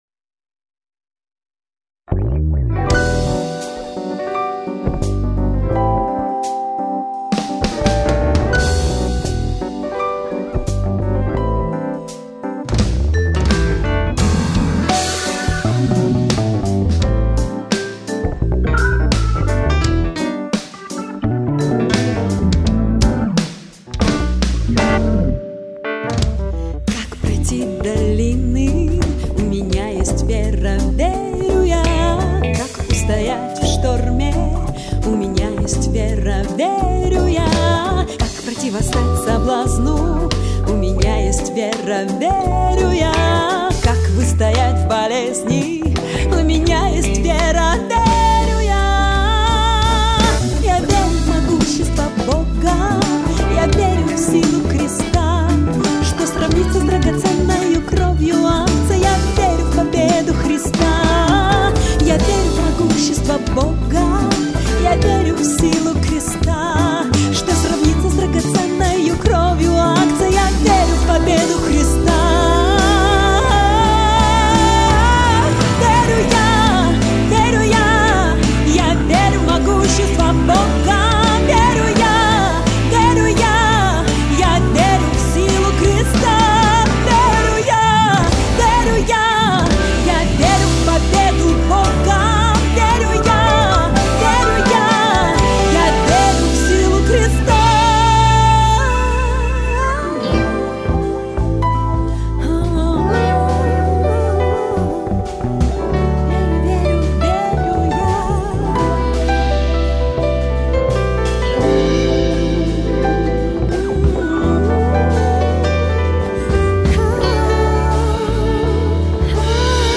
1450 просмотров 562 прослушивания 37 скачиваний BPM: 85